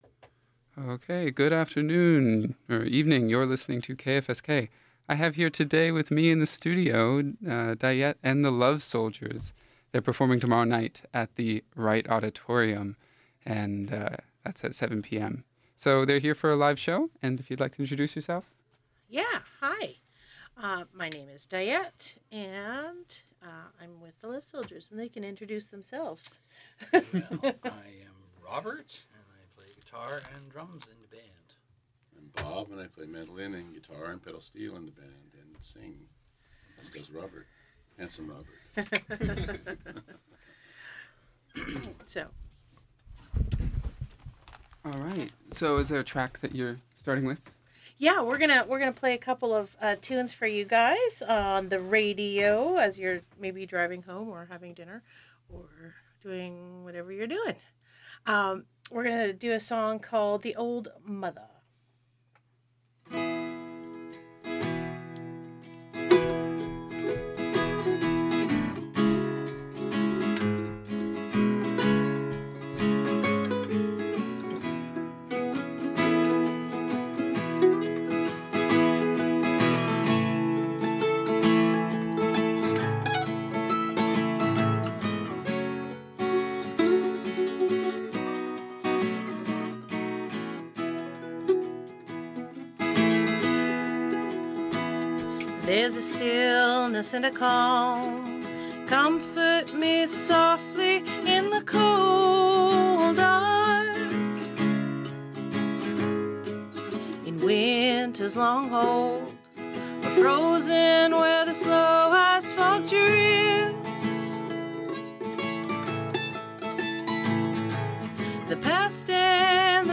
performed a short live acoustic set here